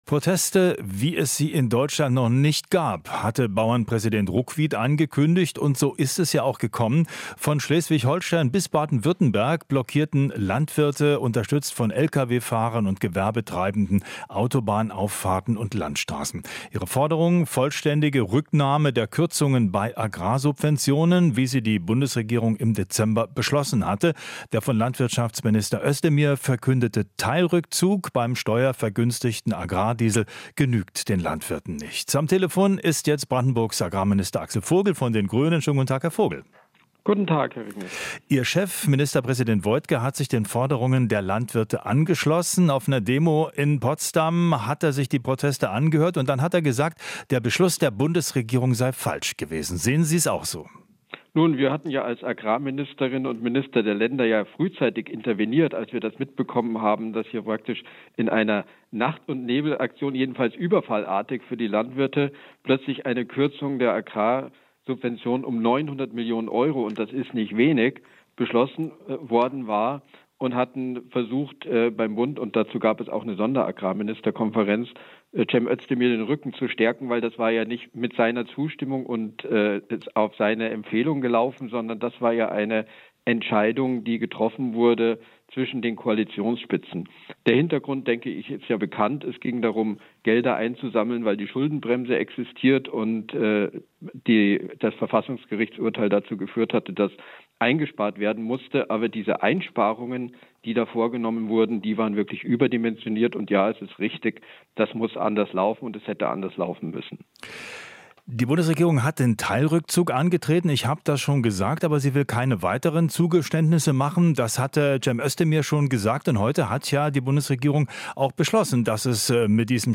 Interview - Vogel (Grüne): "Man muss den Landwirten mehr Zeit geben"